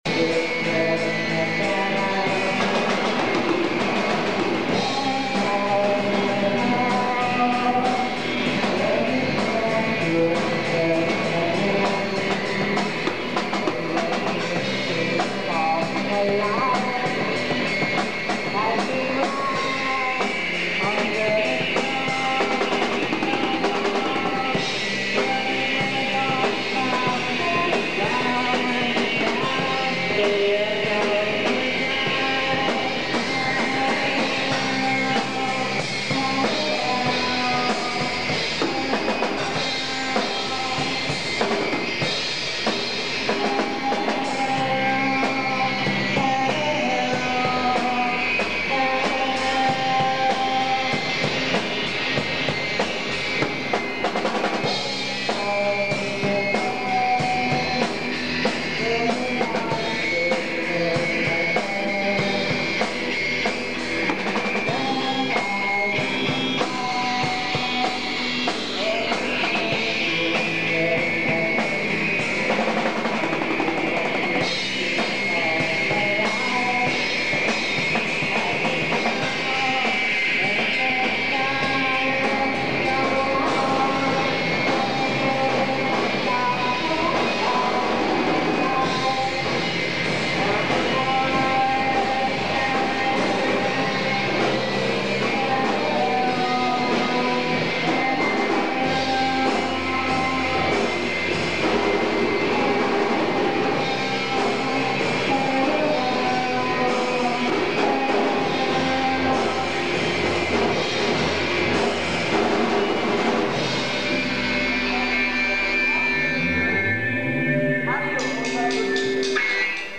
КОНЦЕРТ В КИНОТЕАТРЕ "ПИОНЕР"
электрическая гитара
ударные.